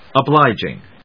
音節o・blíg・ing 発音記号・読み方
/ʌˈblaɪdʒɪŋ(米国英語)/